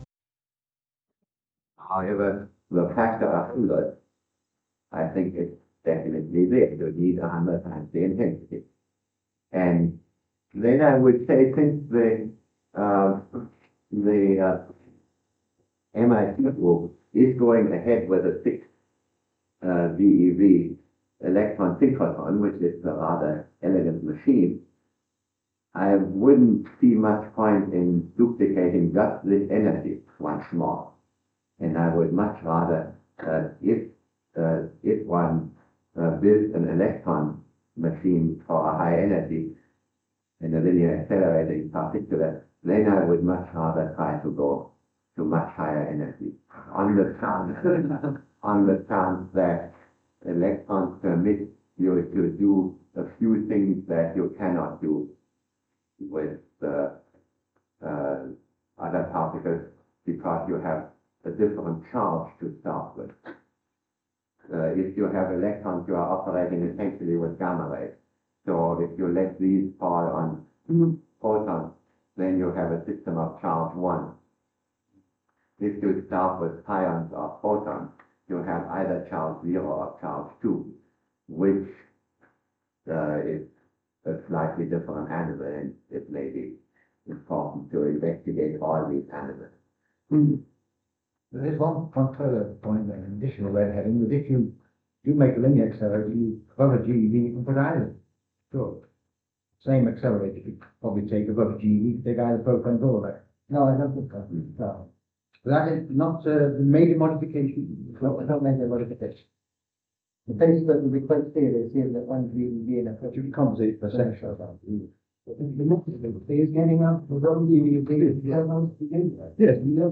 The discussions were recorded. (The original 1955 reel-to-reel tapes were re-recorded onto cassette in 1982; the tape/side numbering scheme is curious.)
Tape 2 sides 3 and 4: General discussion.